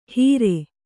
♪ hīre